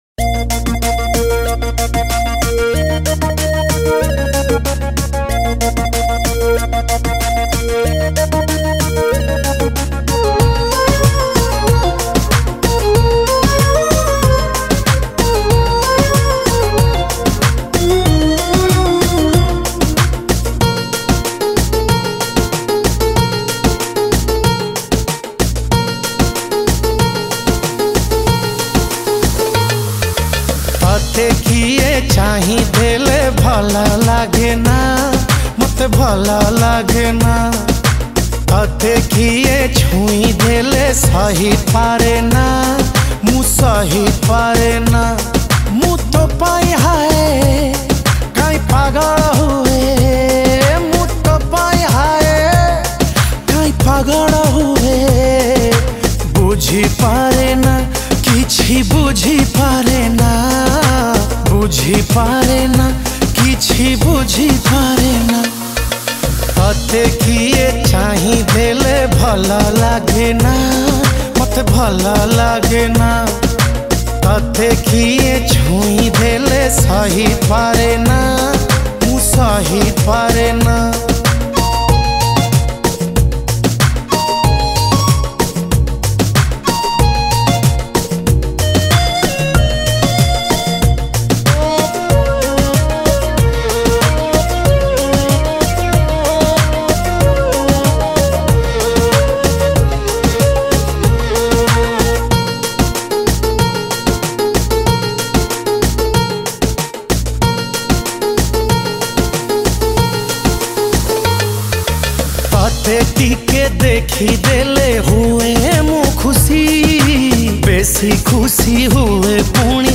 Romantic Song
Odia Songs